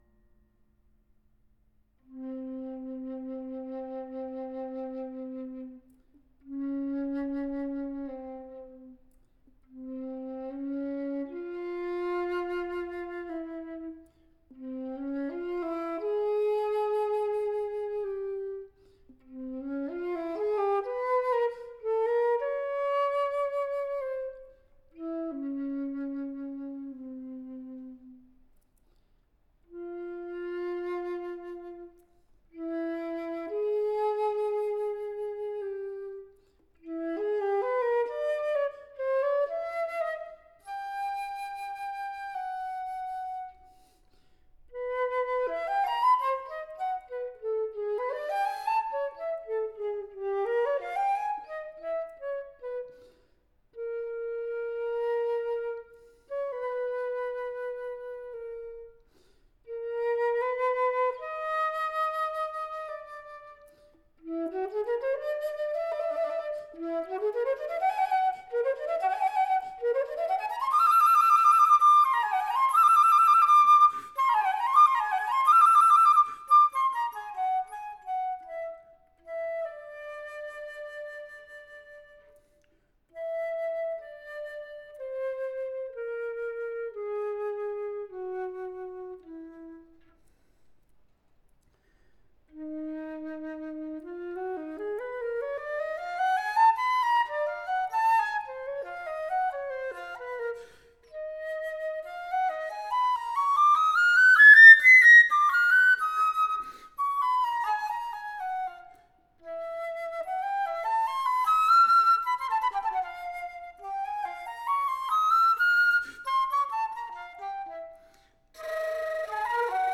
for solo flute